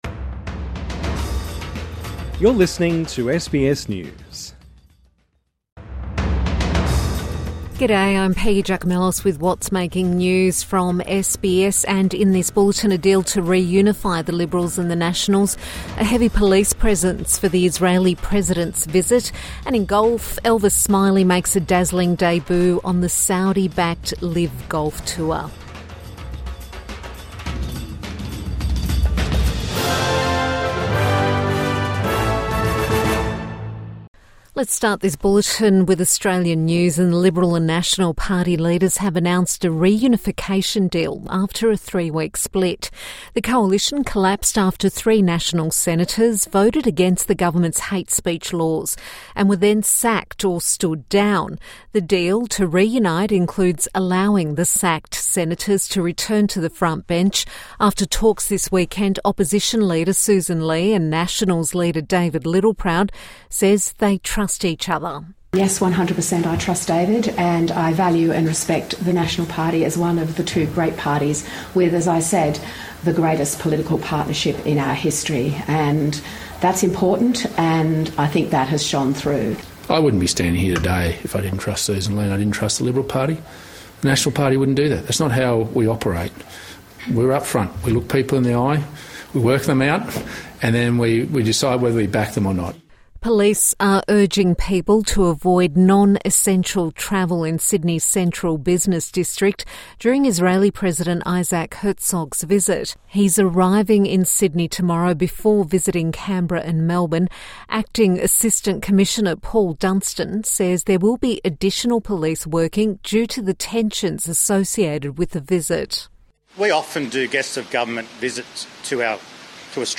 A deal to reunify the Liberals and Nationals | Evening News Bulletin 8 February 2026